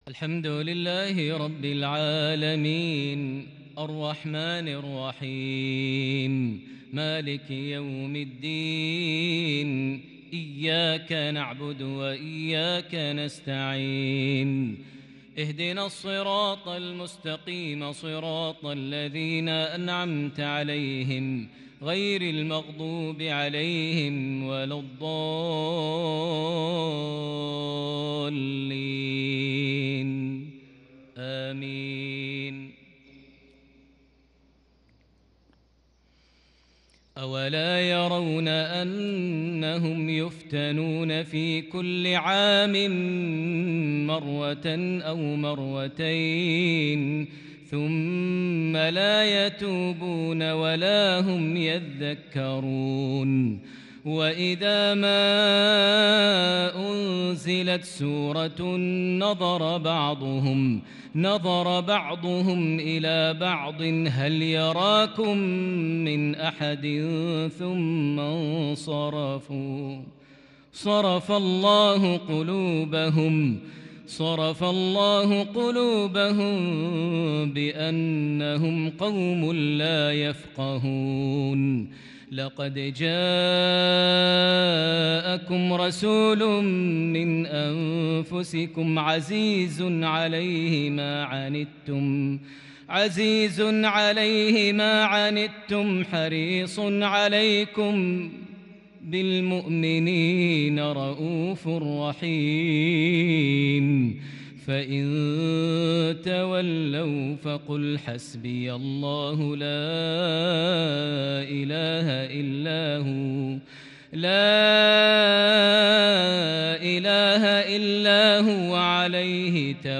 مغربية فذه لخواتيم سورتي التوبة - الفتح | 20 رجب 1442هـ > 1442 هـ > الفروض - تلاوات ماهر المعيقلي